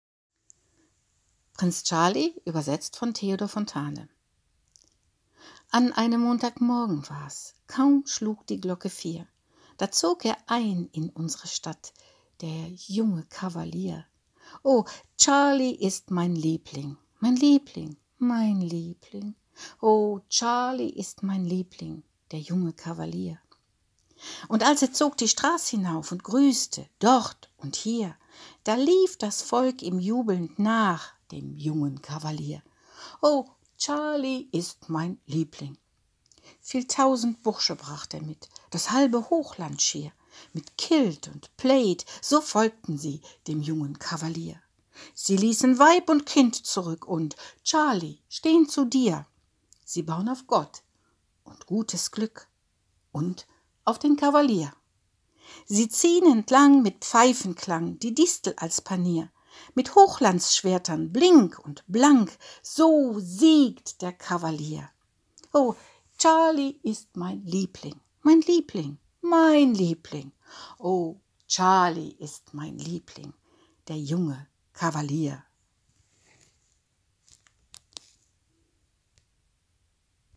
Volkslied auf Bonnie Prince Charlie, von Fontane übersetzt und auf die Originalmelodie zu singen: „An einem Montagmorgen war’s Kaum schlug die Glocke vier, Da zog er ein in unsre Stadt, Der junge Kavalier.